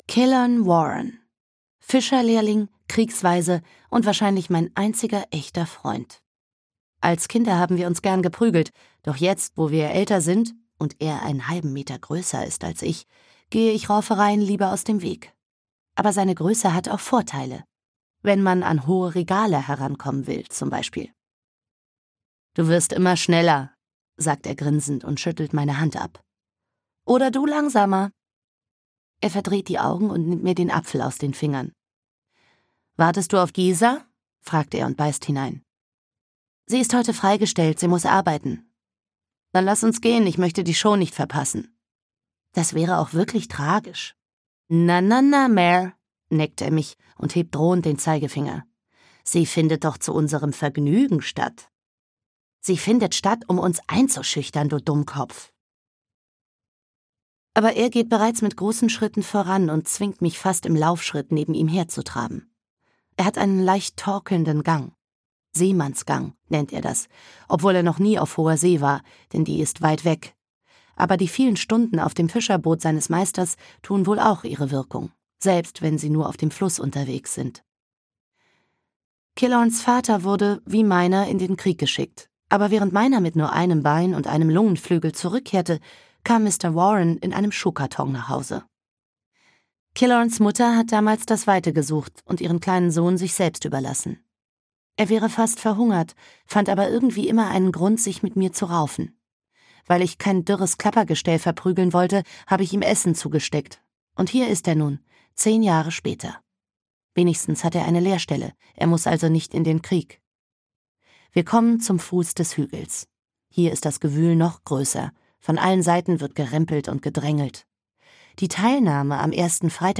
Die rote Königin (Die Farben des Blutes 1) - Victoria Aveyard - Hörbuch